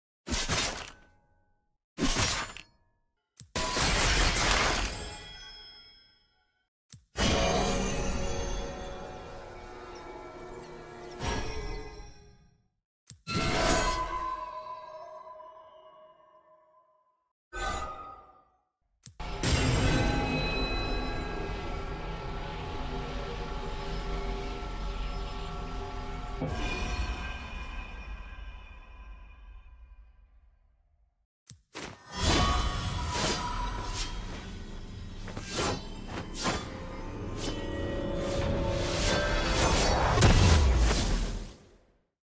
💥 Ult Sounds, Voice, & More 💥